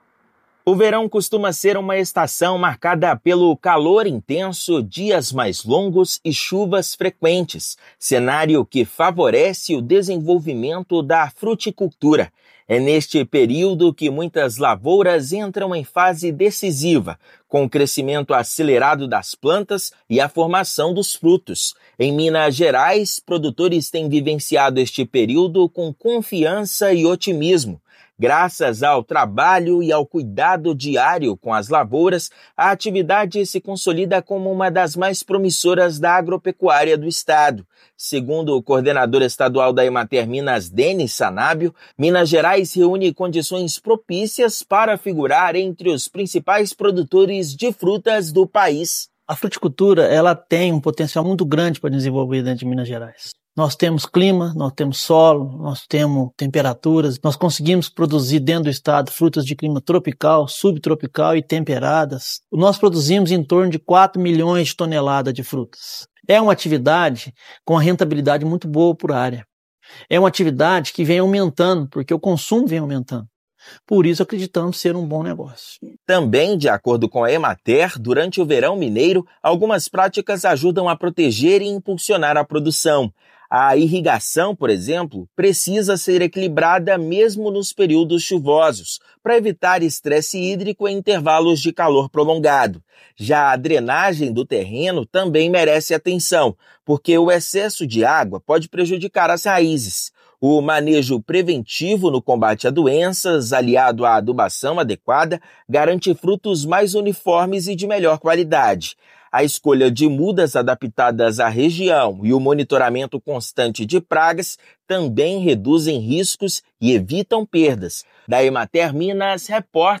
Produtores recebem orientações sobre manejo e produtividade da lavoura durante o período. Ouça matéria de rádio.